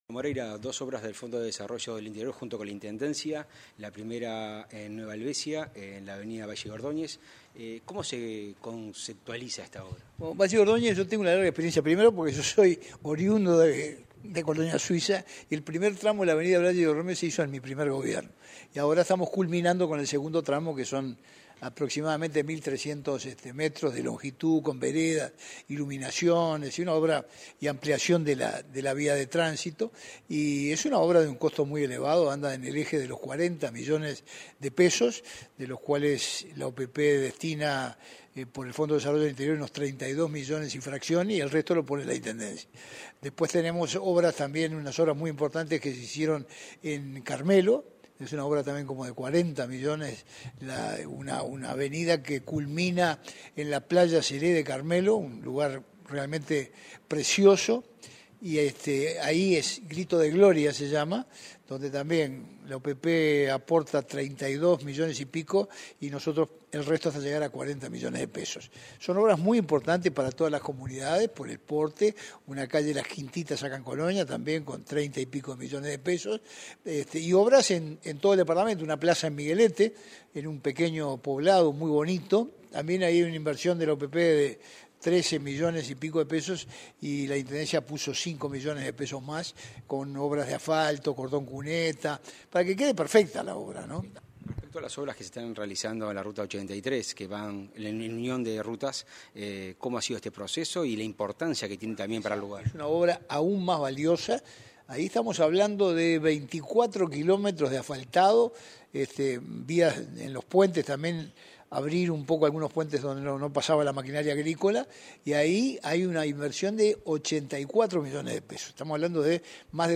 Entrevista al intendente de Colonia, Carlos Moreira